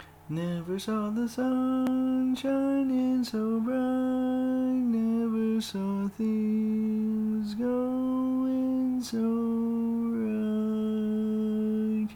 Key written in: F Major
Each recording below is single part only.